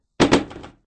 box_drop.ogg